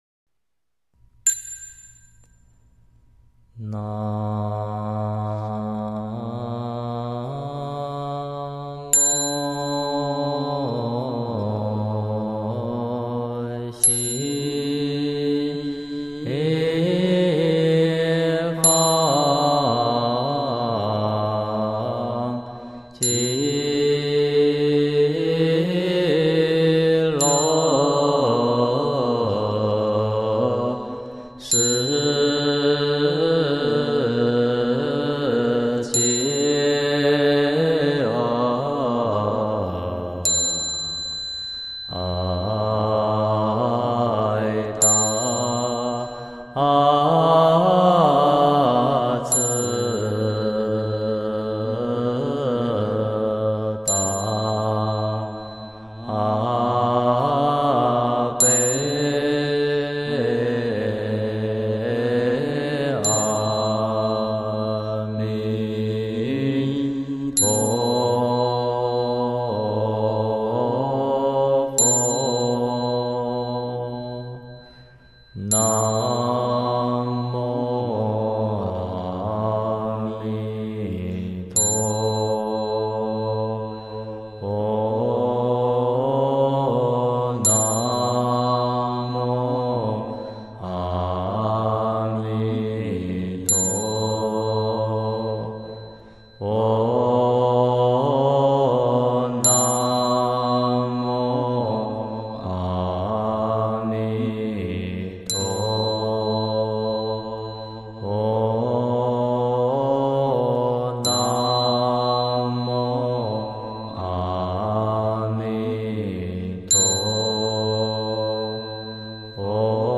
助念佛號-文殊講堂 南無阿彌陀佛聖號 六字轉四字 Mp3